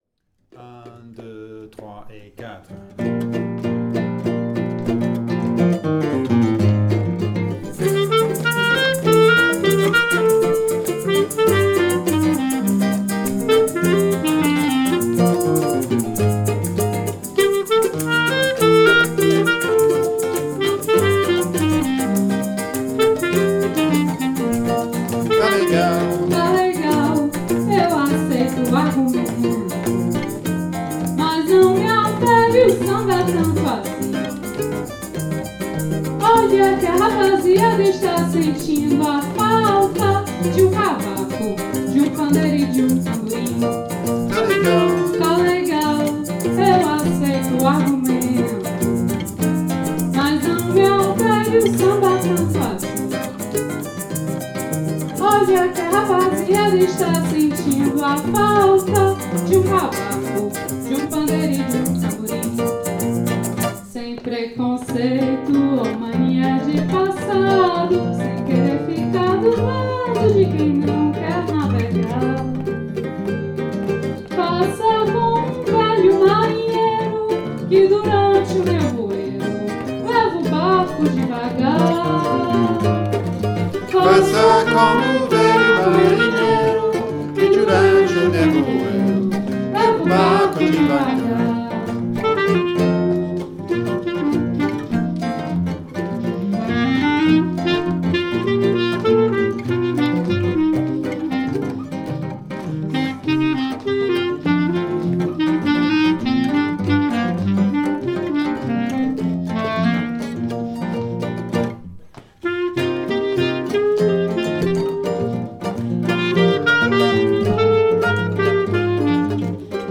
Som Espacial
Rec atelier
Session sans percussions
Argumento_sans_percus.mp3